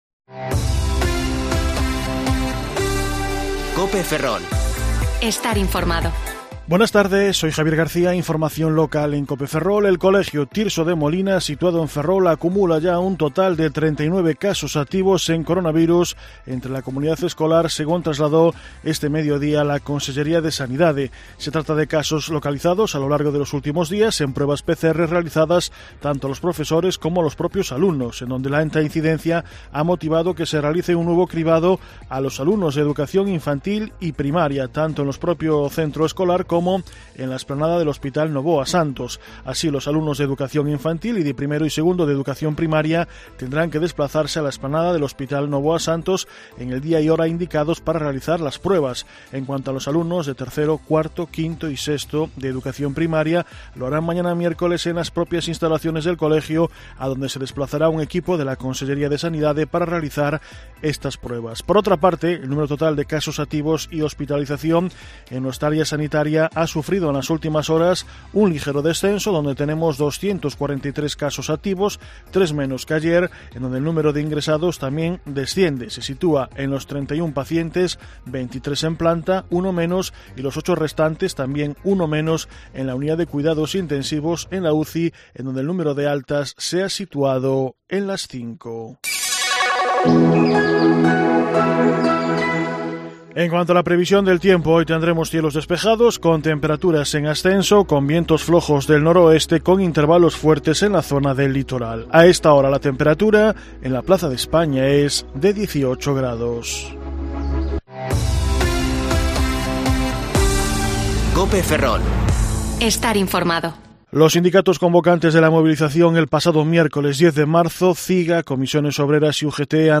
Informativo Mediodía COPE Ferrol 16/3/2021 (De 14,20 a 14,30 horas)